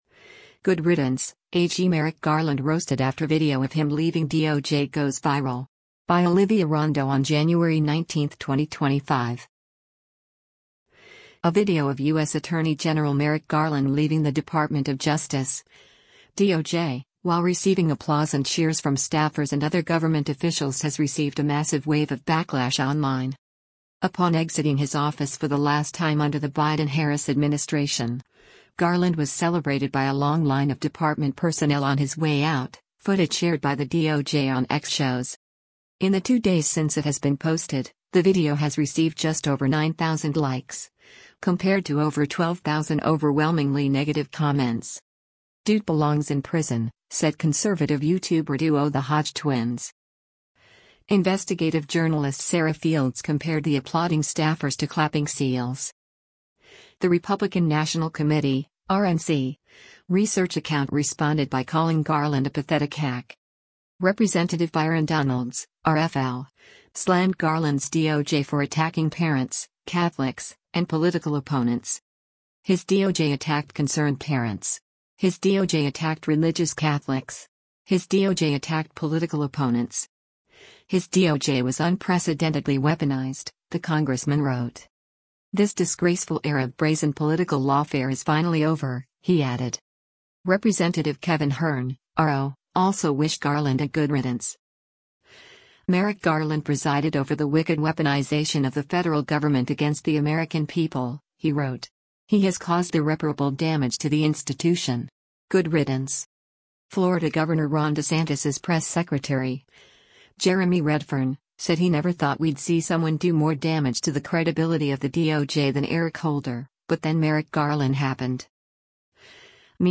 A video of U.S. Attorney General Merrick Garland leaving the Department of Justice (DOJ) while receiving applause and cheers from staffers and other government officials has received a massive wave of backlash online.